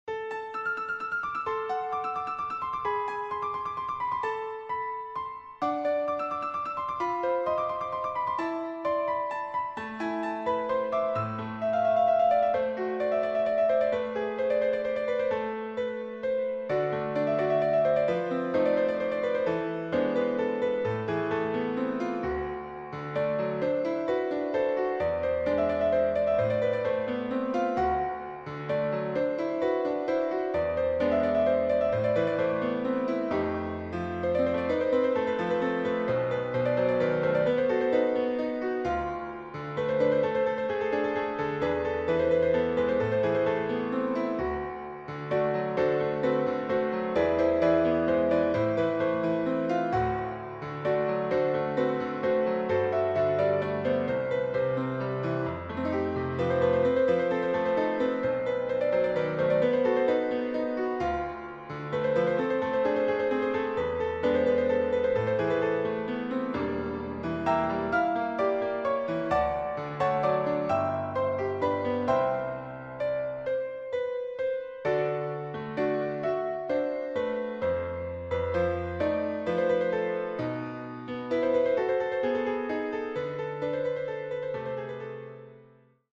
Müəllif: Azərbaycan Xalq Mahnısı
həm şən, həm də həsrətli bir havaya malikdir